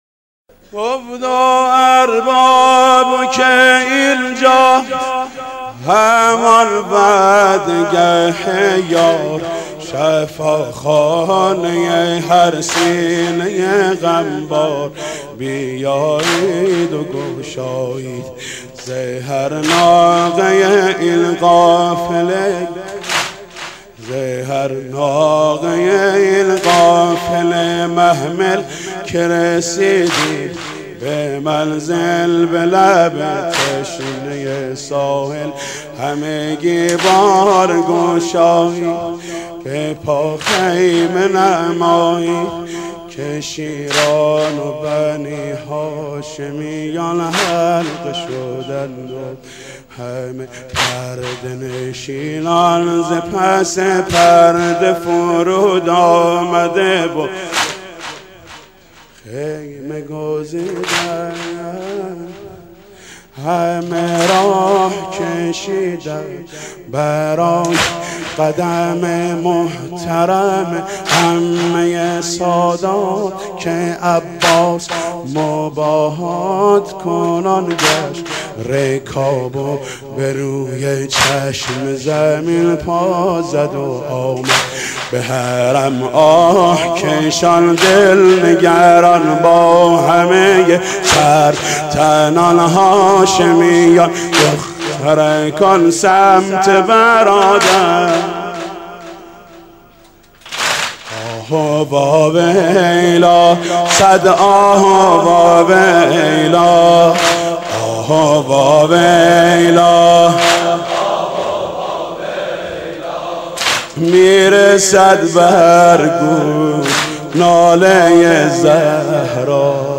برچسب ها: شهدای ایران ، صوت ، مداحی ، ناله زهرا ، شهید ، شهدا ، ایران شهید